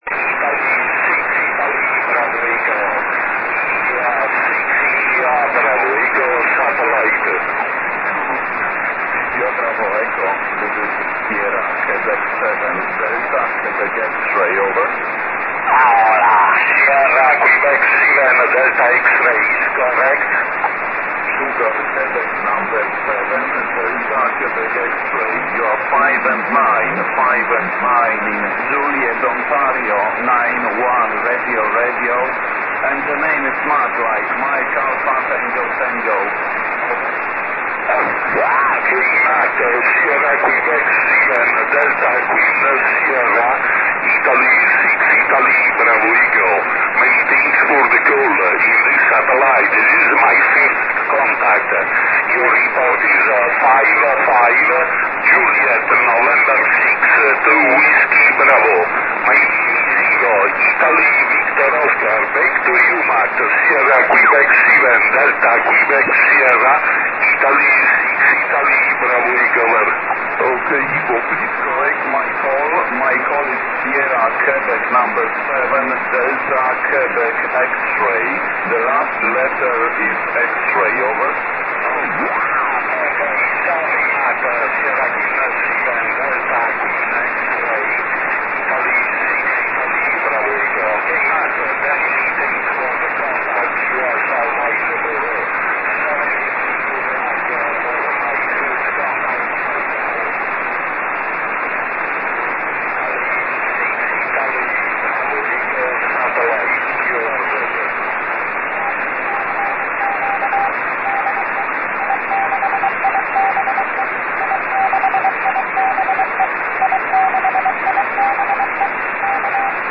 Satellite Cinese XW-1 HOPE-1 (HO-68) Linear Trasponder 144/435 Mhz SSB video
xw1_ssb.mp3